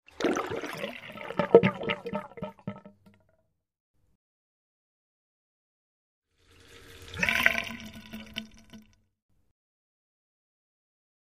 Wash Basin Trap x2